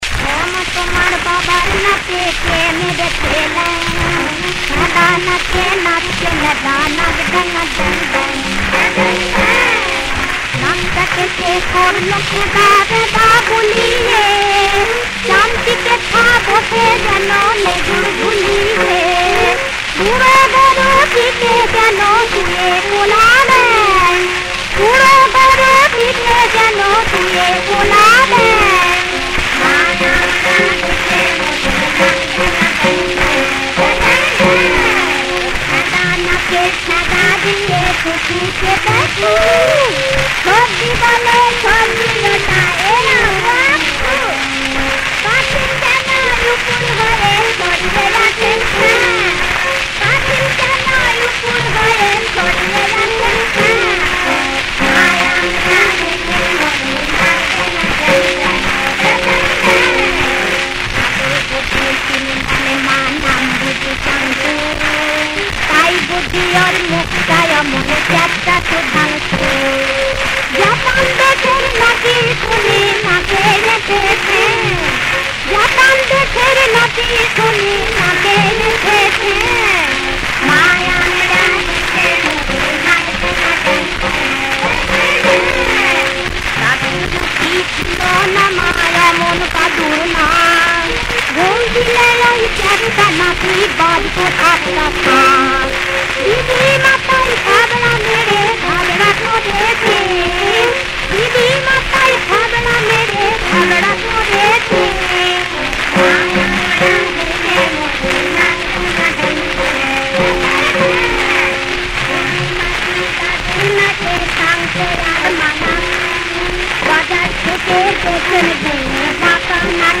• বিষয়াঙ্গ: [শিশুতোষ]
• তাল: দাদরা